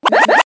One of Koopa Troopa's voice clips in Mario Kart Wii